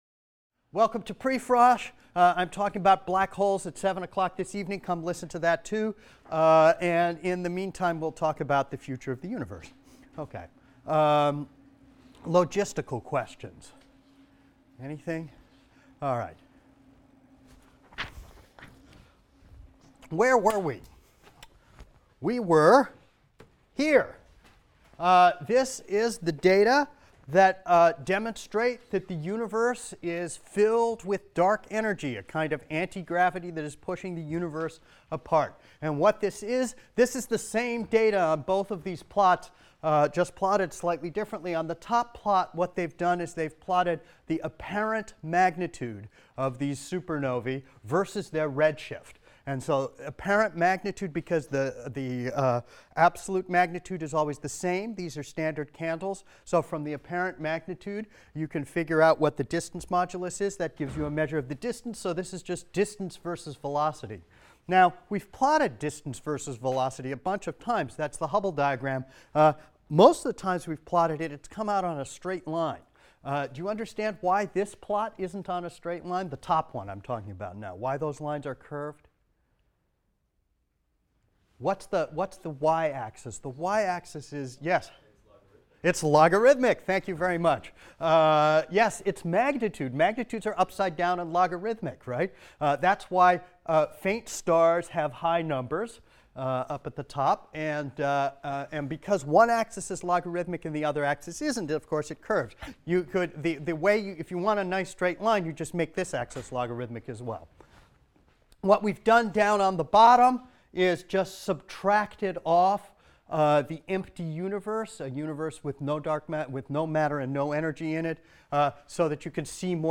ASTR 160 - Lecture 21 - Dark Energy and the Accelerating Universe and the Big Rip | Open Yale Courses